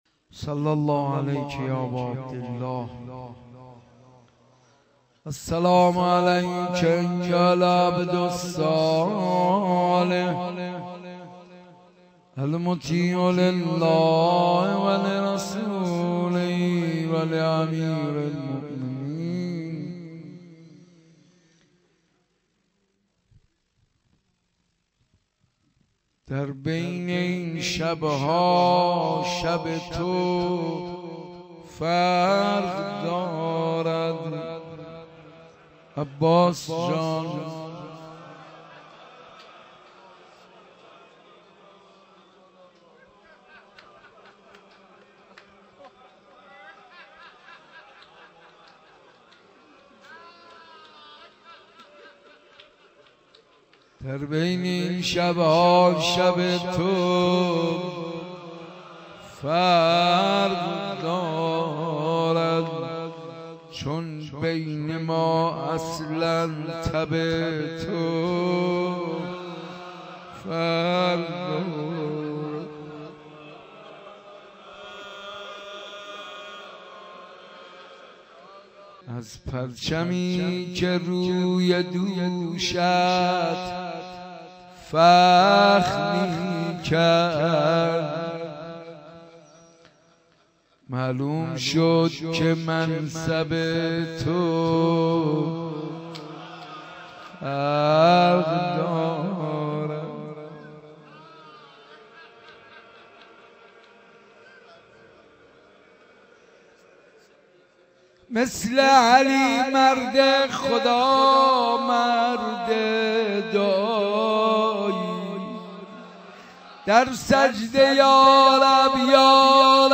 شب بیست ونه ماه محرم در حسینیه بیت الزهرا
با مداحی حاج منصور ارضی برگزار شد